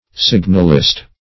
Search Result for " signalist" : The Collaborative International Dictionary of English v.0.48: Signalist \Sig"nal*ist\, n. One who makes signals; one who communicates intelligence by means of signals.